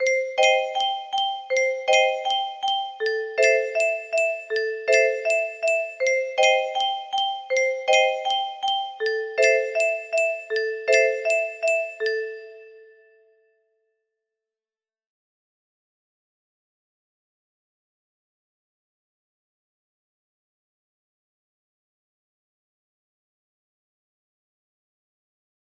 C Cmin G G x2 A Amin EE x2 A music box melody